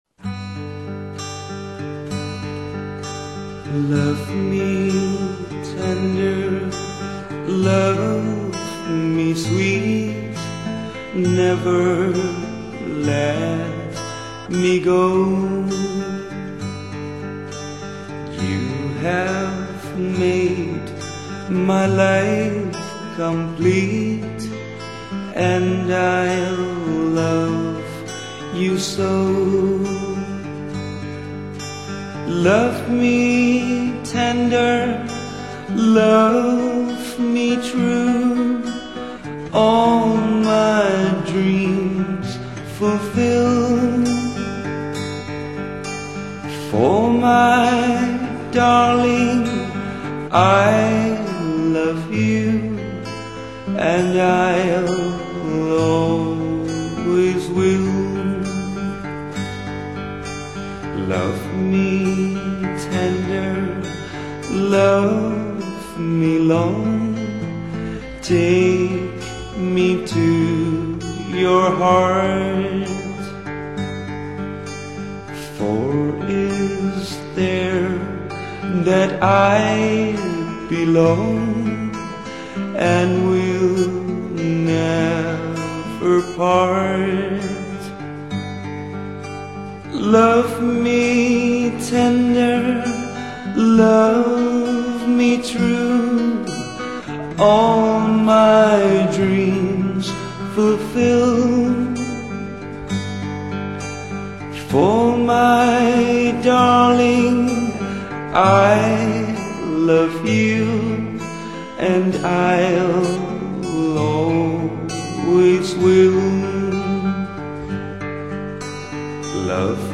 语    种：纯音乐
[广告语] 乡村宁静雅致，民谣清新质朴。